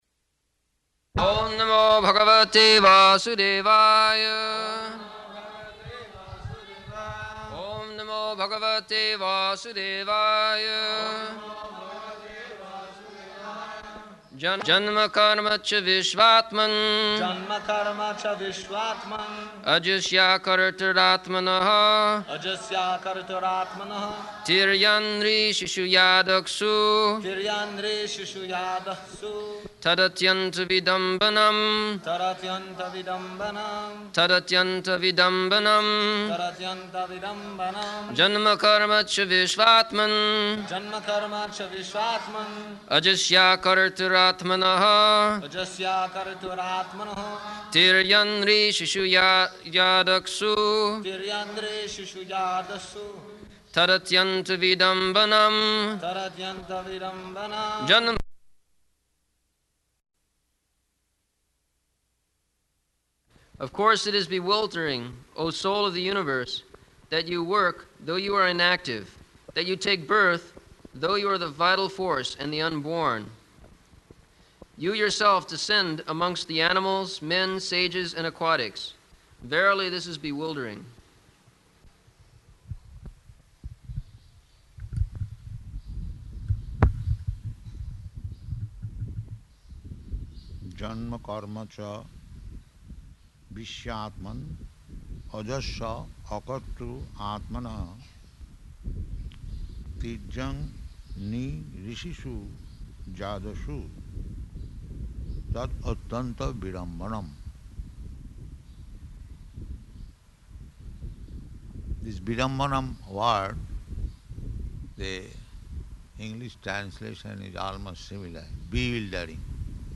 October 10th 1974 Location: Māyāpur Audio file
[devotees repeat]